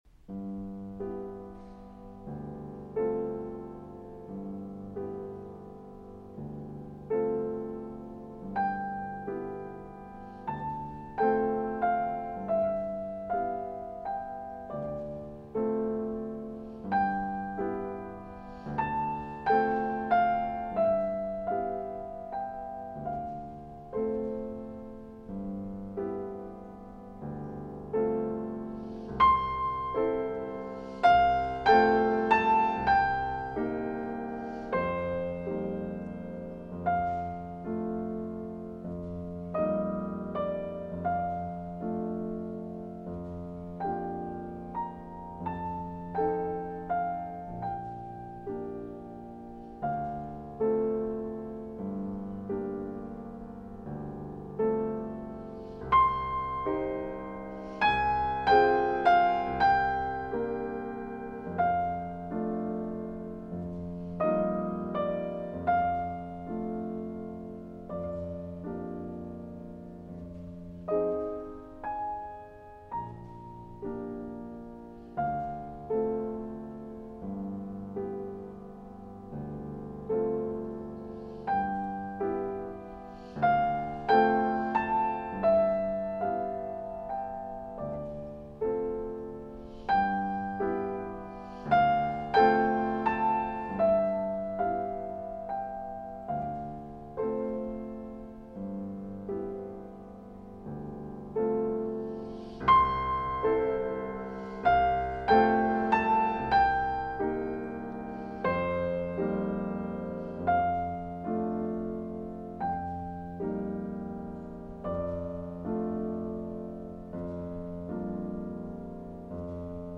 Le tre Gypnopedie che inserisco sono brani di un livello altissimo; niente virtuosismo, ma una ricerca della nota perfetta, per suscitare sentimenti molto intensi, di malinconia, di tristezza, di assoluto. Accordi in minore che toccano l’anima.